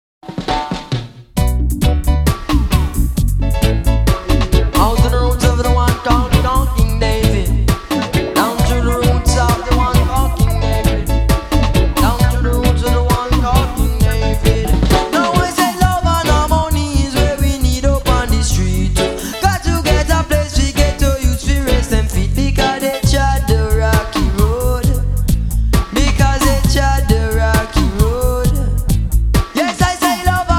batterie